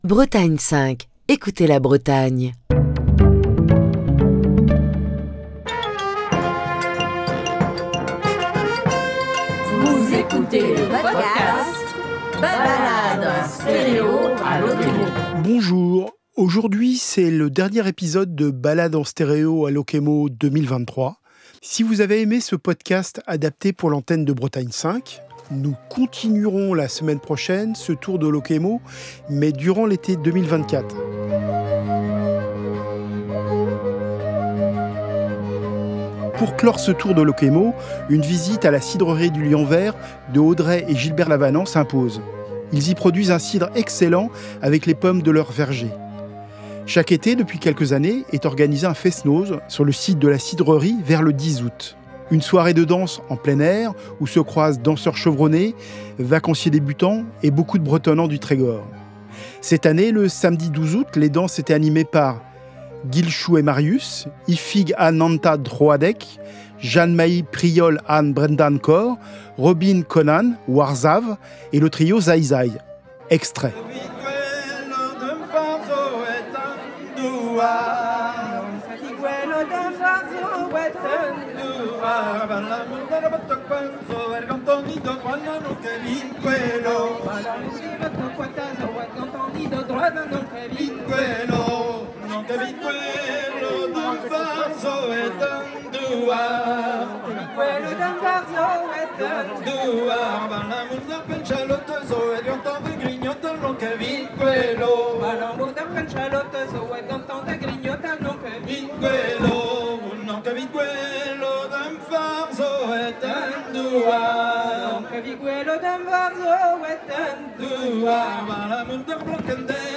Ce matin, nous allons faire un tour à la cidrerie du Lianver où se déroule un fest-noz très prisé qui a lieu tous les ans, aux alentours du mois d'août. Nous y étions pour l’édition 2023.
La semaine prochaine, nous entamerons les reportages enregistrés durant l’été 2024... mais pour l’instant, nous allons continuer à danser au fest-noz du Lianver !